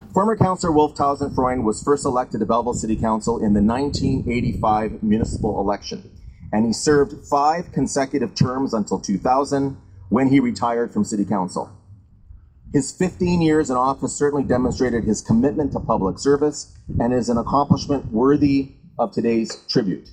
Wednesday, Belleville Mayor Mitch Panciuk along with city councillors and invited guests, honoured the retired Superior Court of Justice judge and former Belleville Council member, with a plaque dedication in the Commons Area on Front Street, across from City Hall.